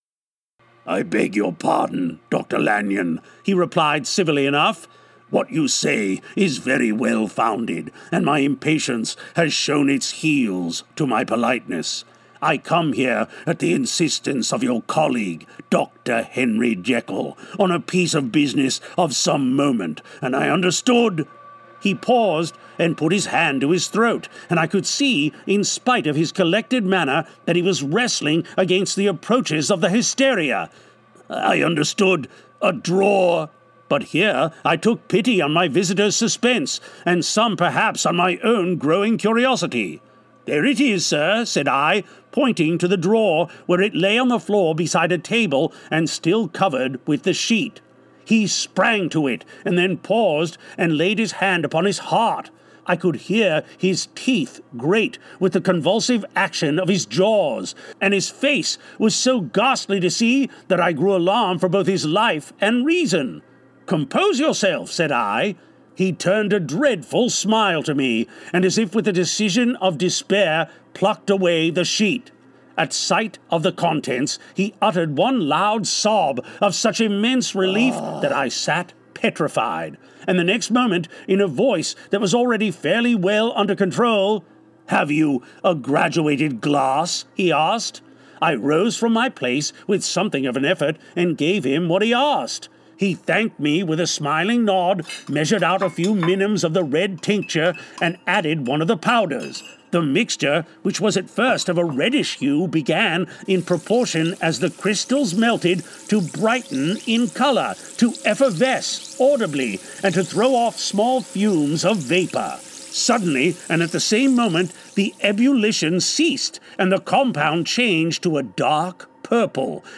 When the brilliant Dr. Henry Jekyll stumbles across a formula that allows his bestial and evil side to come forward, he must deal with the deadly consequences.  Long hailed as one of the great horror novels of all time, SoundCraft Audiobooks presents this creepy tale with added music and sound effects, putting the listener in the center of the action!